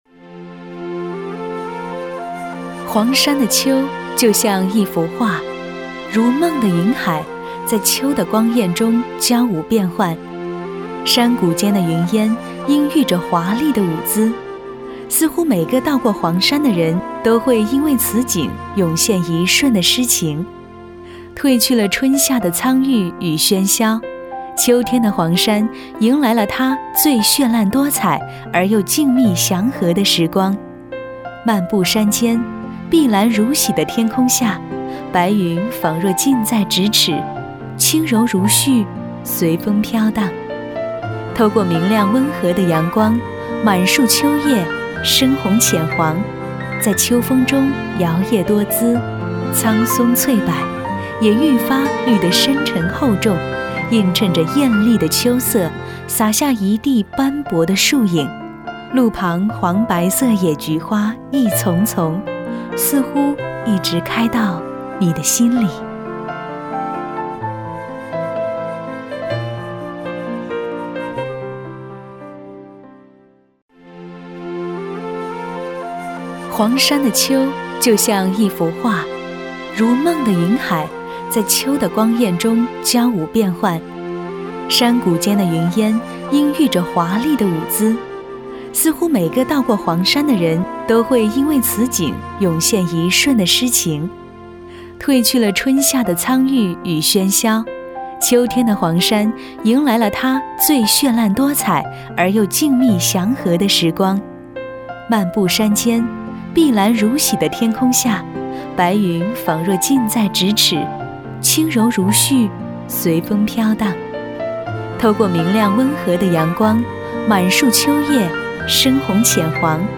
• 女S19 国语 女声 旅游导览-黄山之秋-景区解说介绍-亲切温和 积极向上|亲切甜美